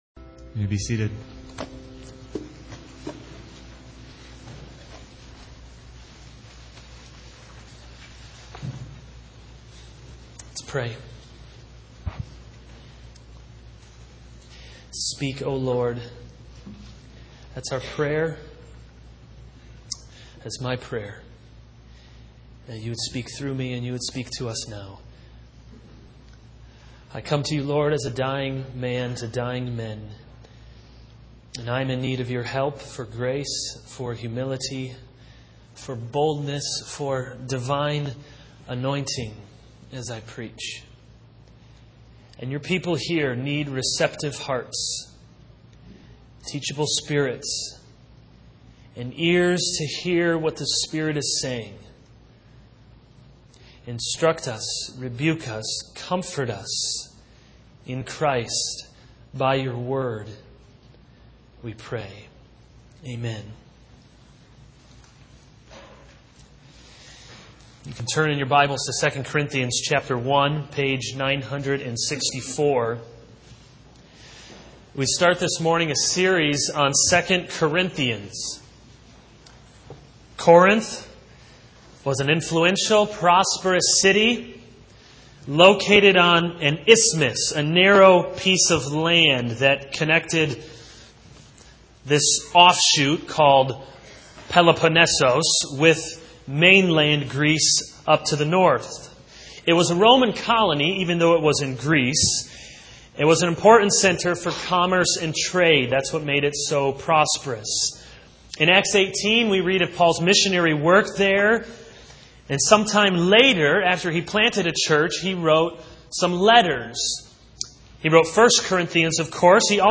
This is a sermon on 2 Corinthians 1:1-7.